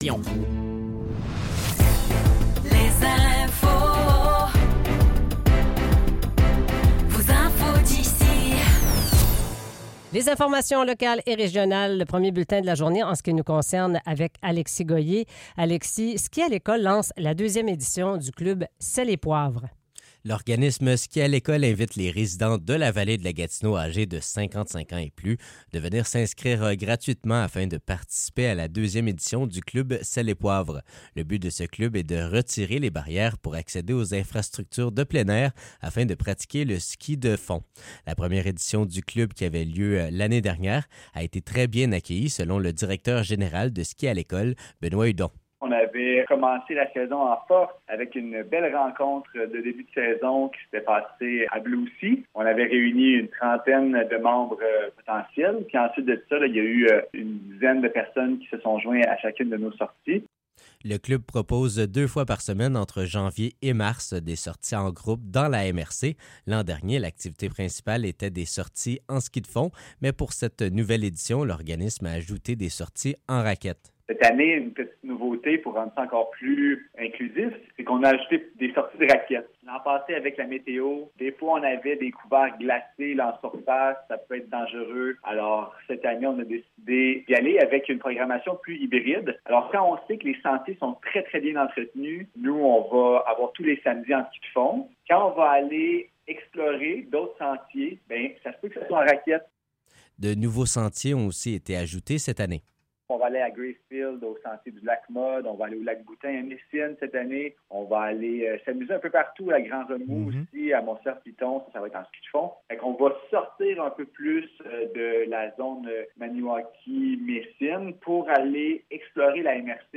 Nouvelles locales - 27 novembre 2024 - 7 h